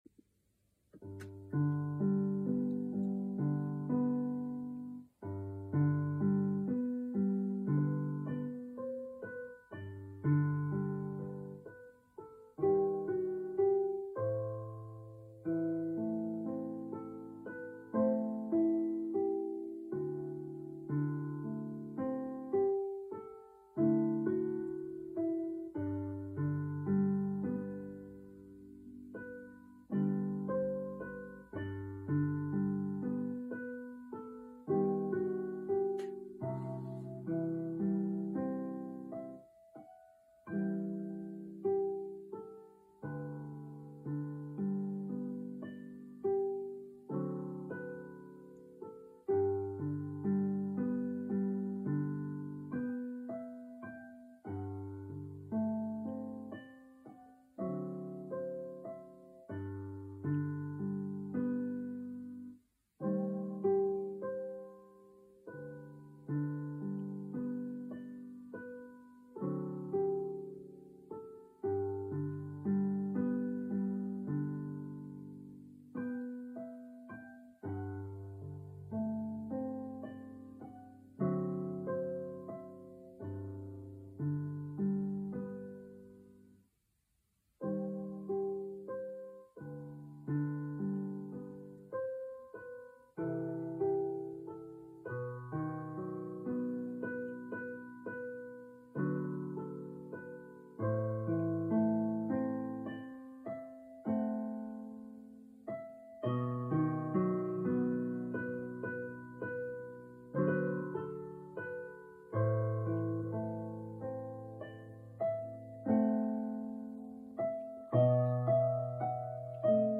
Az éjjelt egy számomra teljesen új  frissen felfedezett darab gyakorlásával zártam (rögzítéskor a nagy lámpalázam sajnos sokat ront az előadásomban...) /  Ho concluso la notte con l'esercizio di una  recentemente scoperta composizione (durante la registrazione l'ansia purtoppo peggiora molto la mia esecuzione...):